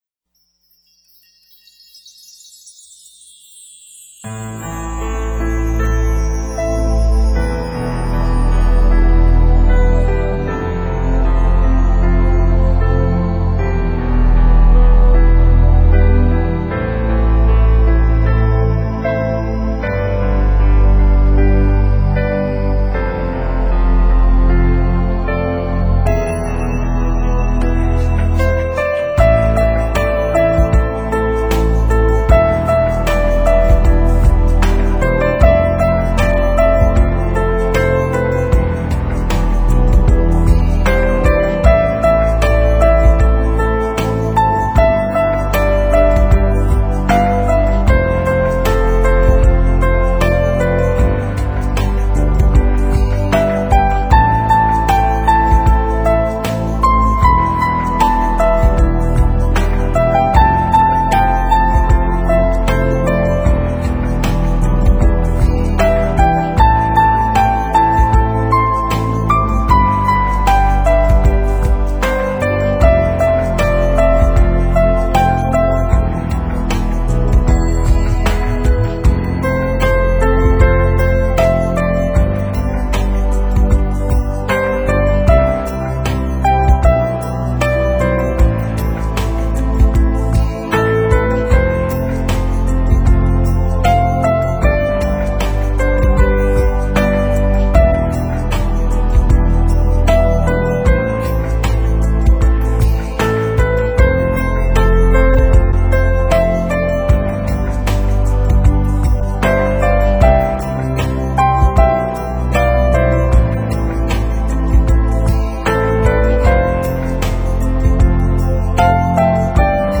旋律优美，节奏虽有些缓慢， 却拥有了那种洒脱和亮丽的色彩。